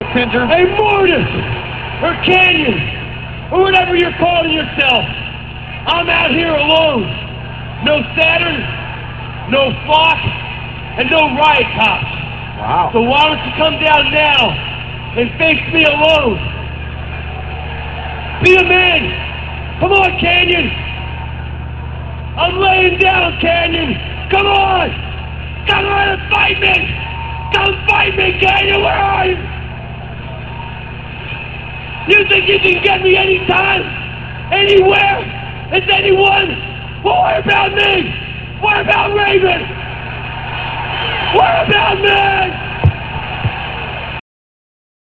- From WCW Thunder - [06.04.98]. Raven goes off on Kanyon's sneak attacks on him & is angry and frustrated at his inability to confront him.